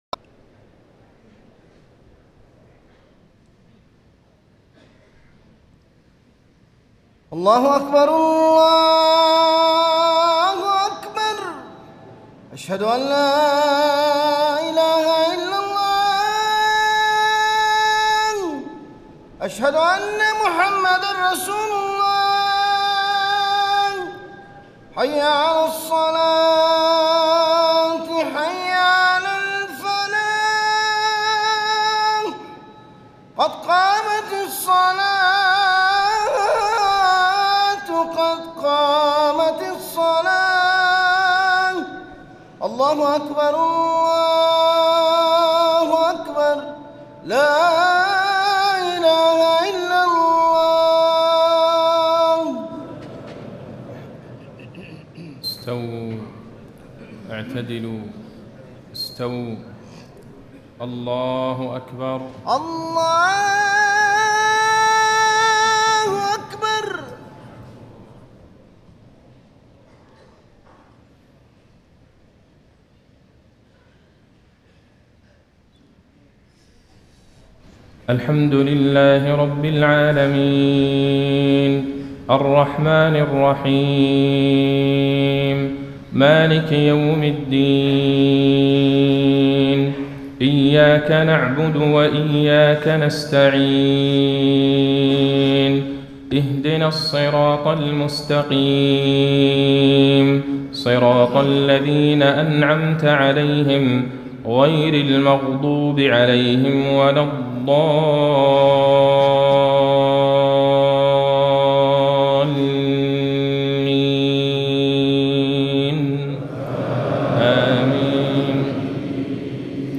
صلاة المغرب 8 - 7 - 1435هـ من سورة البقرة > 1435 🕌 > الفروض - تلاوات الحرمين